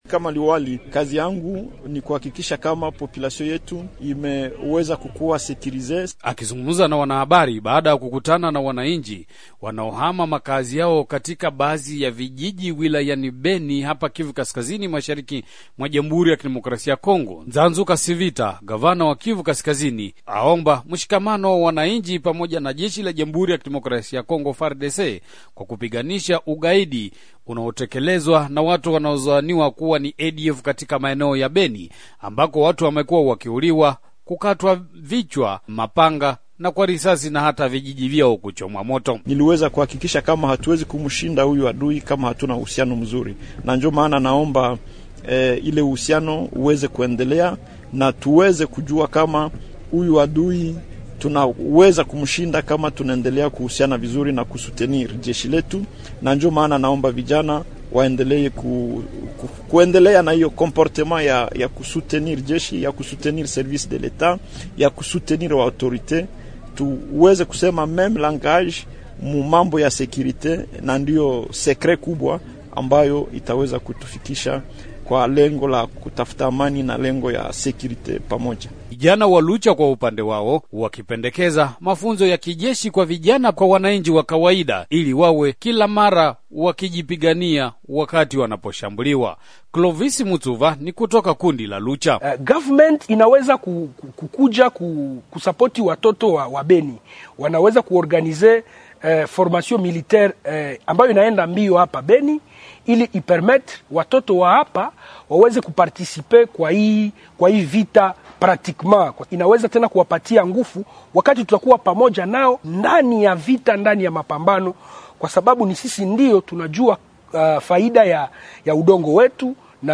Mwandishi wa VOA